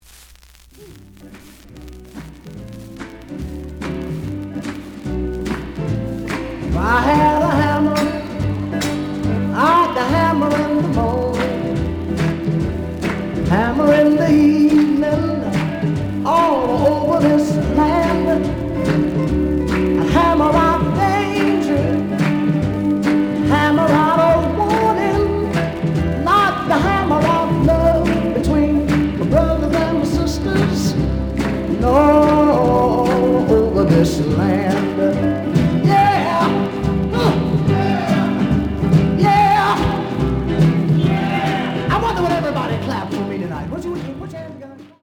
The audio sample is recorded from the actual item.
●Genre: Soul, 60's Soul
Slight noise on beginning of both sides, but almost good.)